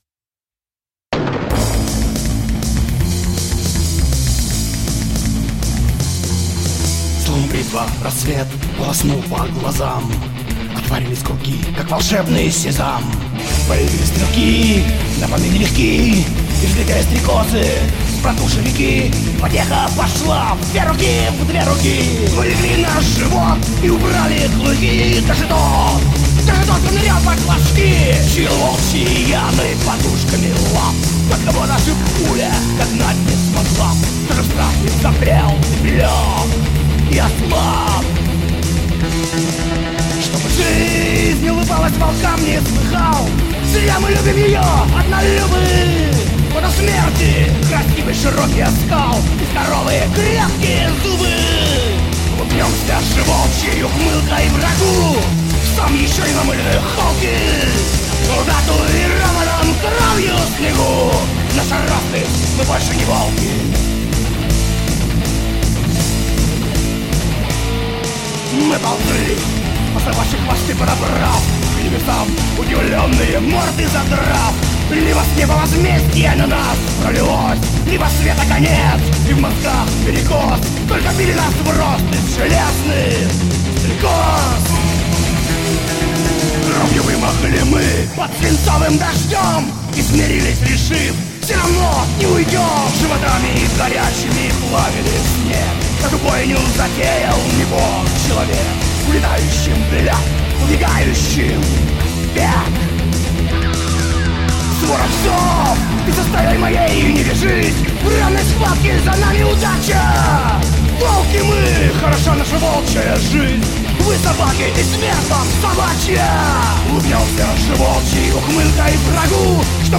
Гитара, бас, перкуссия, голос